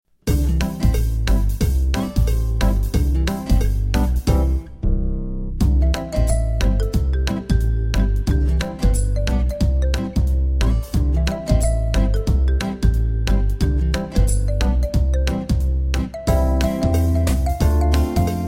instrumental clip